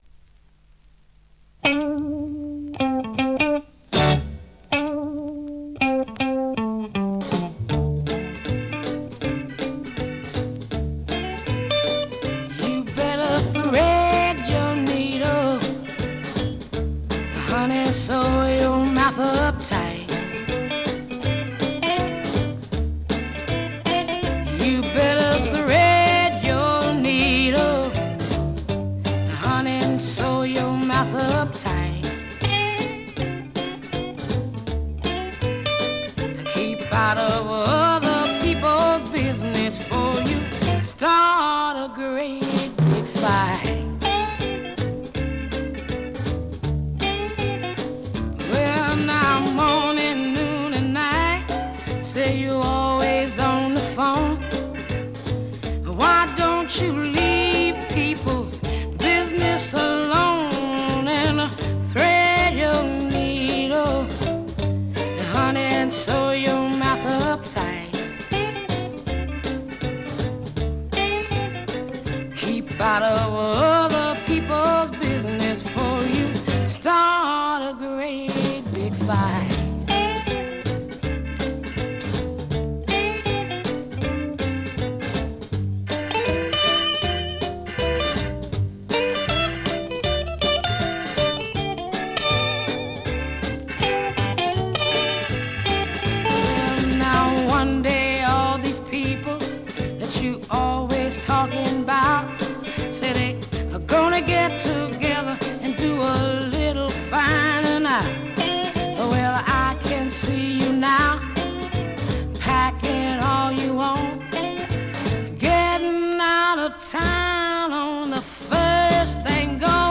Male / female duet R&B Soul mod
Rare and fine French EP soul copy!